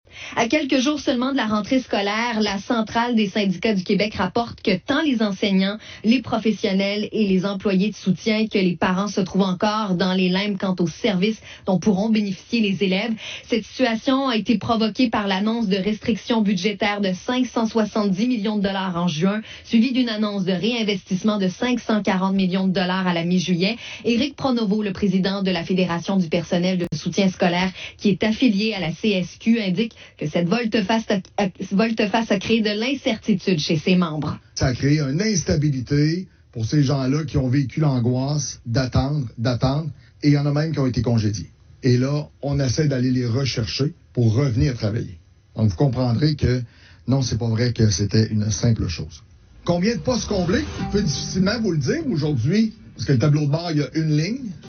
Bulletin de nouvelles de Cogeco